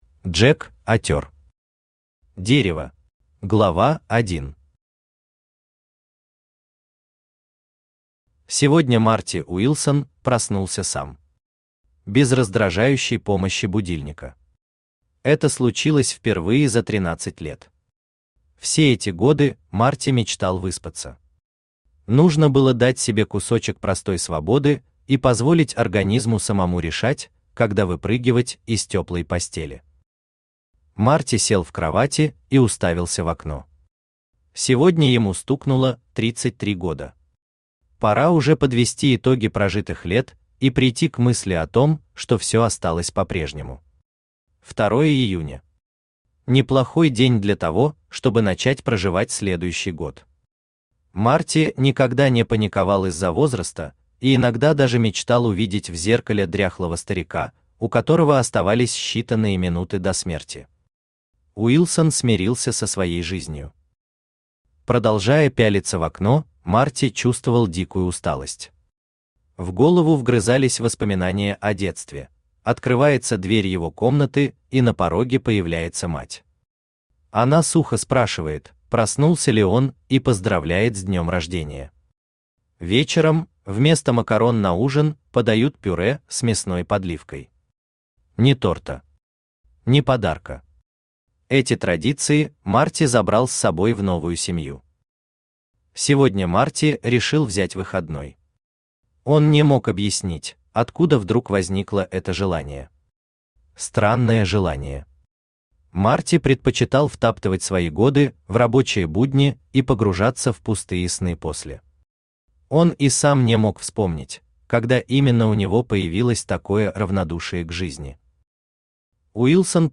Аудиокнига Дерево | Библиотека аудиокниг
Aудиокнига Дерево Автор Джек Атер Читает аудиокнигу Авточтец ЛитРес.